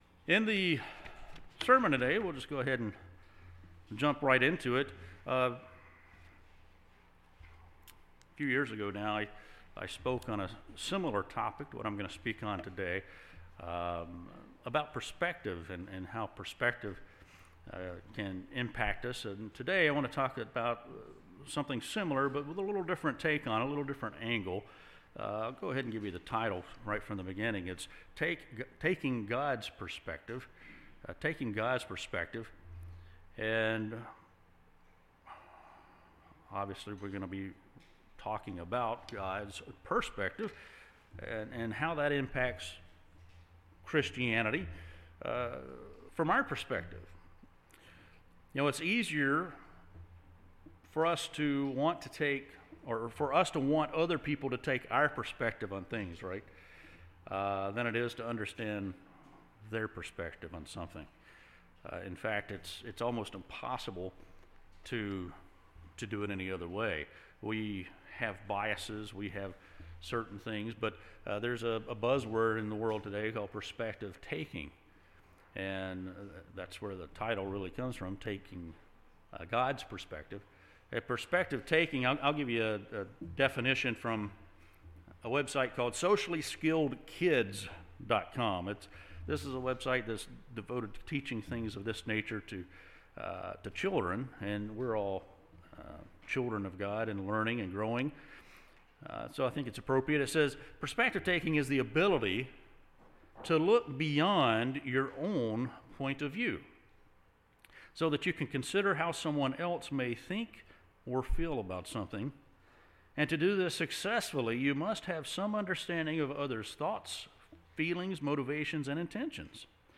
Given in Jacksonville, FL